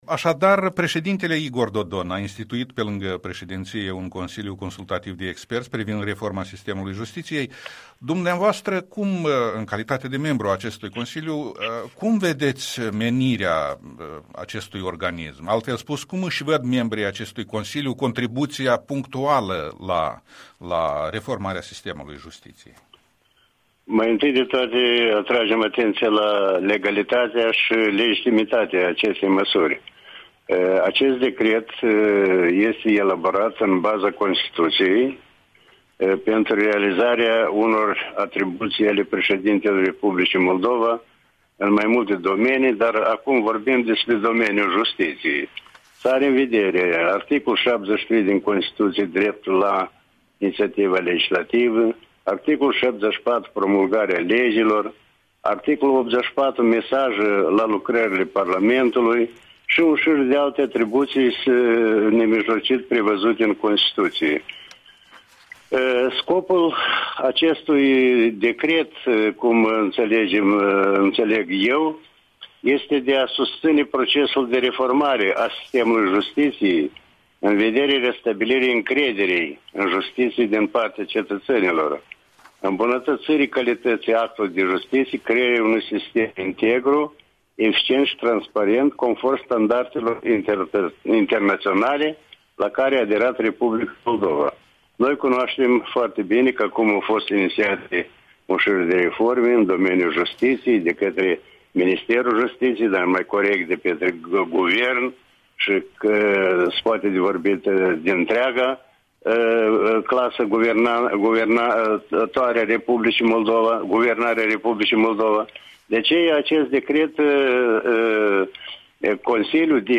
Interviul matinal cu Victor Pușcaș, membru al noii Comisii prezidențiale de experți